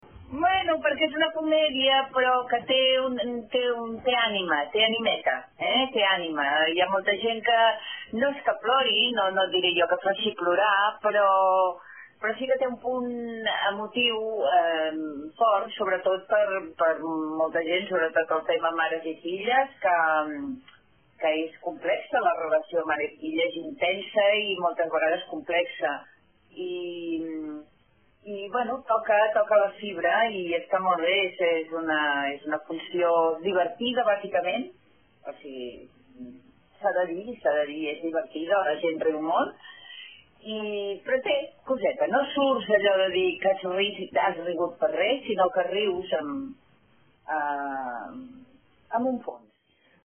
Declaracions de Mont Plans: